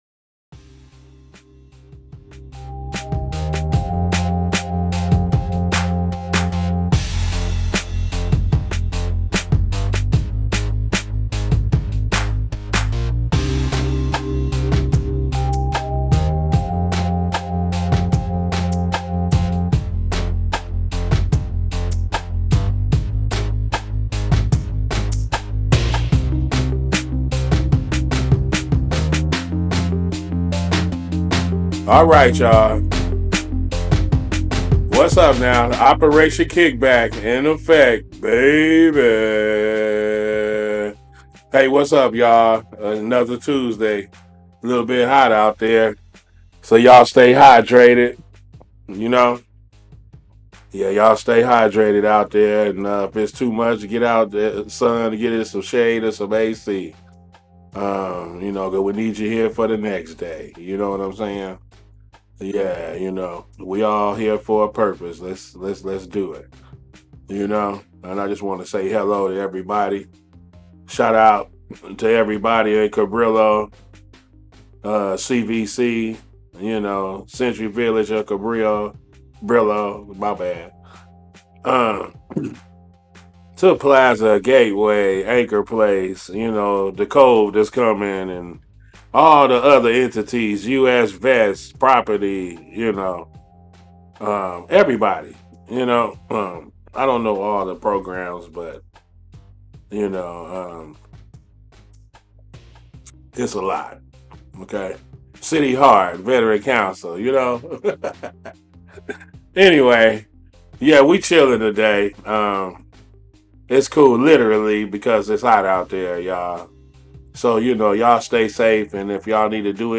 This episode of Operation KickBack aired live on CityHeART Radio on Tuesday July 9, 2024 at 1pm.